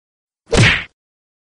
Punch Sound Effect-sound-HIingtone